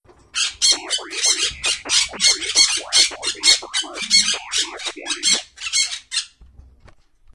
Captain Redbeard's parrot, Polly, has been making strange noises since the captain locked away his treasure. We recorded the bird, but we can't make heads or tails of it.
Look for the text hidden in the low tones.